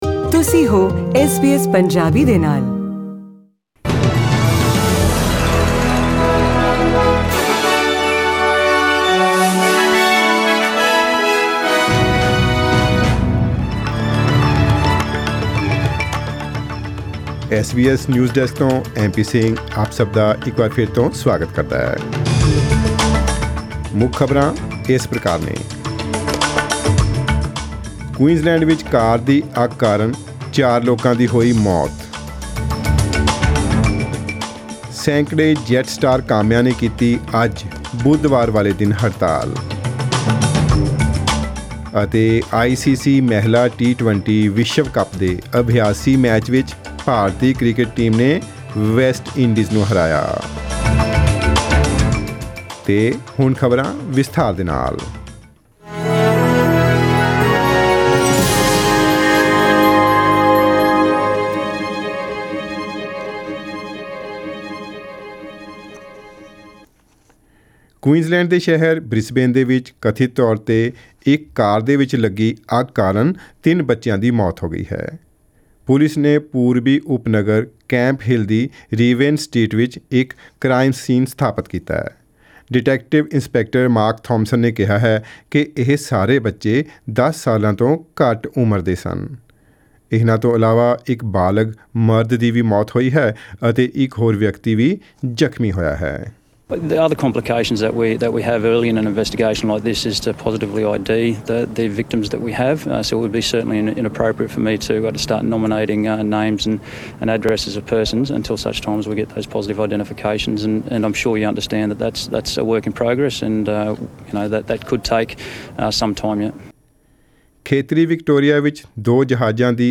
In today’s news bulletin:  Four people dead after a car fire in Queensland; Hundreds of Jetstar workers take strike action today and Indian women cricket players defeated West Indies in ICC –T20 world cup’s practice match.